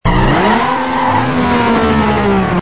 Home gmod sound vehicles enzo